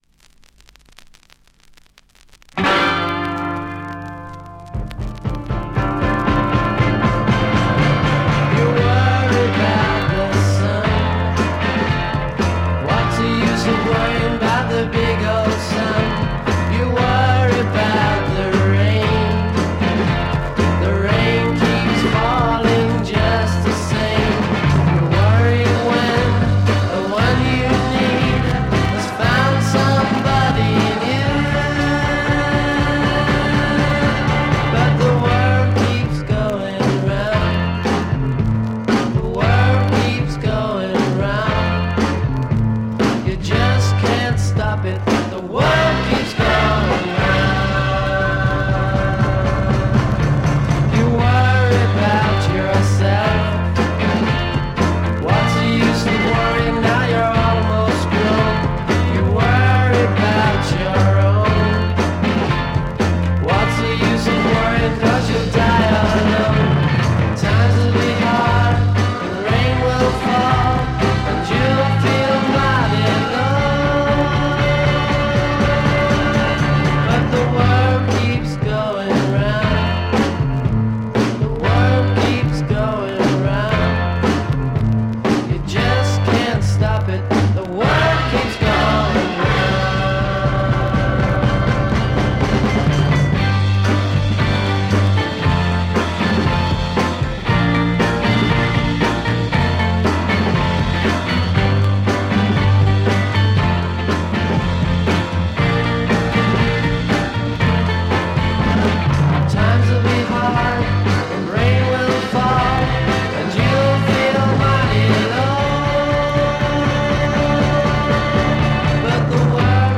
Classic UK Freakbeat, French EP issue.